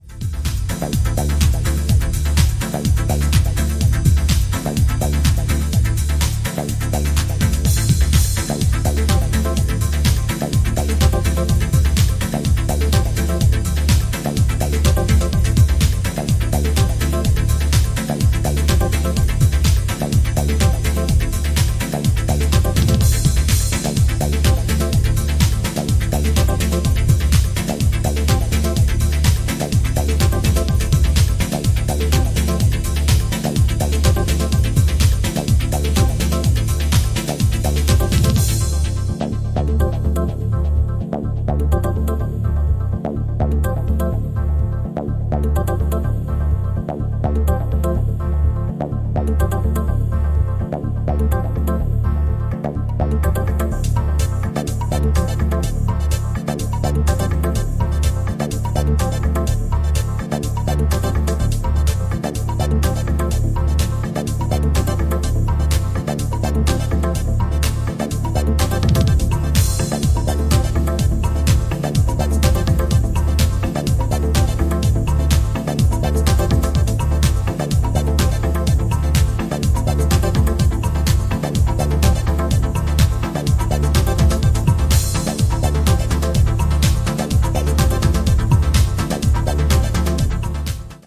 ジャンル(スタイル) TECH HOUSE / DEEP HOUSE